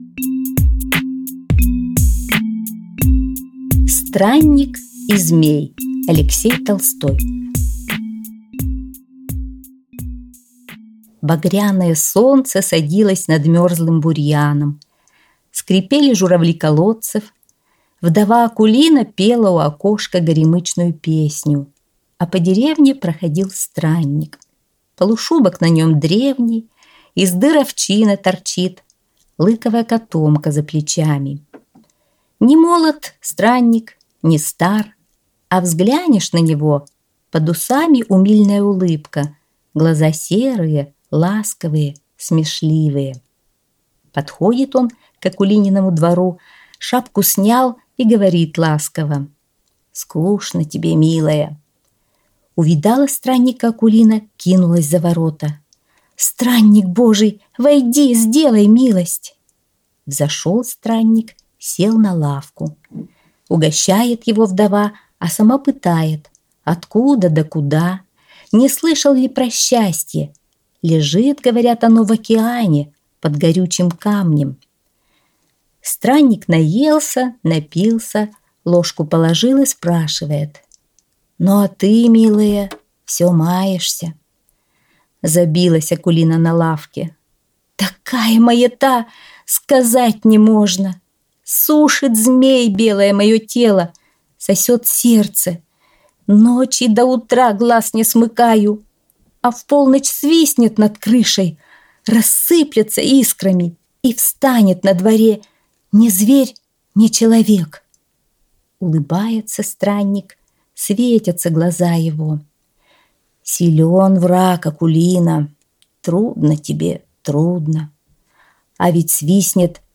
аудиосказки для 12+ летдля 9-10-11 лет